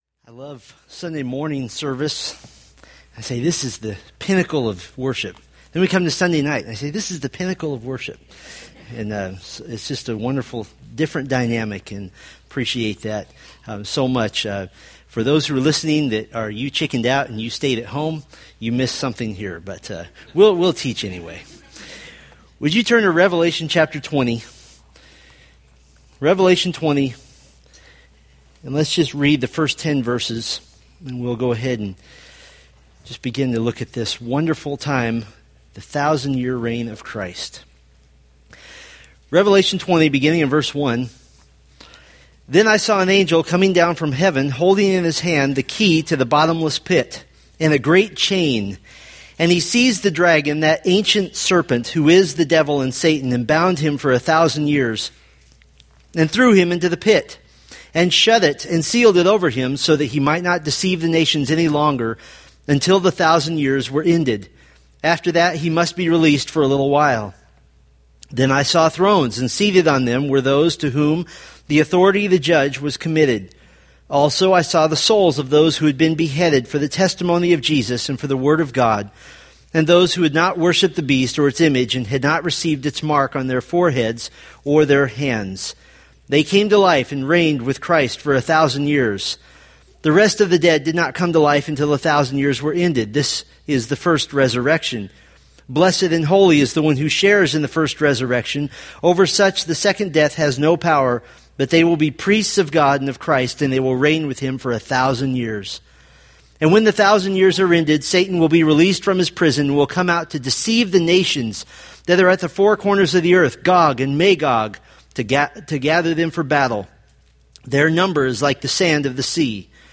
Revelation Sermon Series